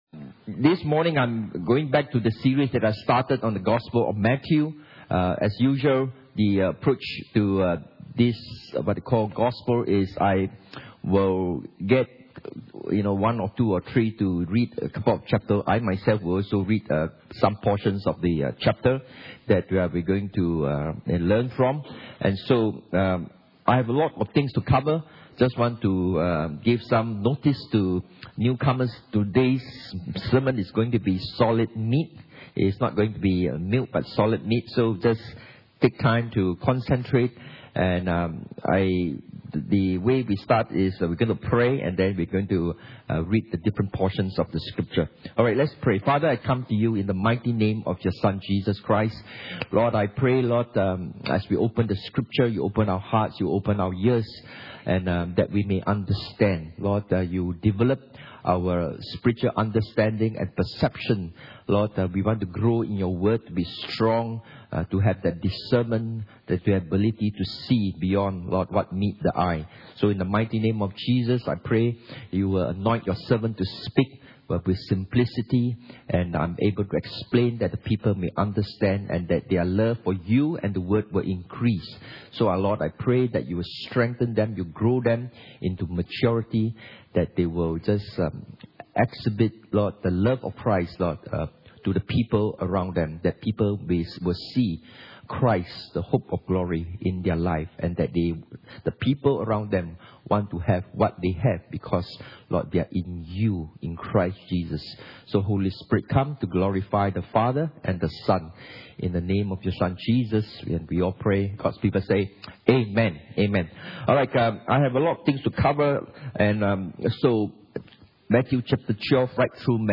Jesus the Greater & Perfect Israel Service Type: Sunday Morning « Contentment in Christ and Life